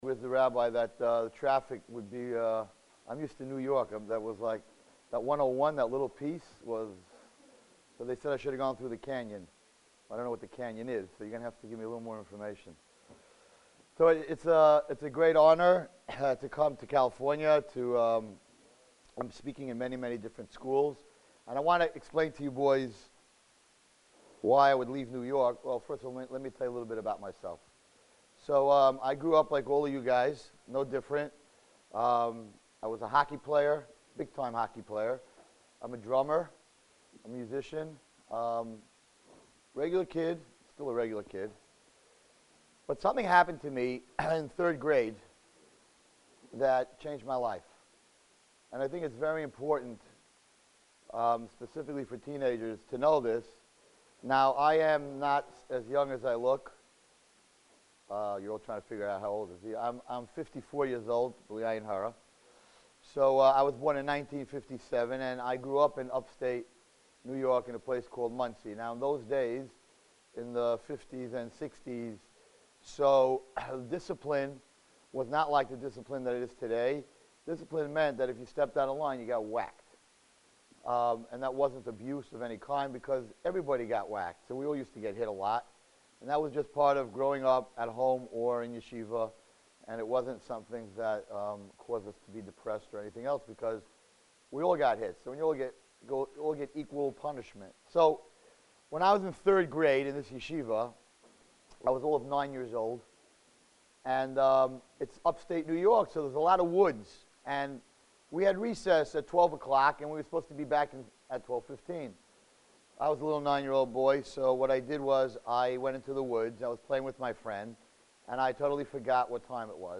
The title of his presentation: Why Be Jewish?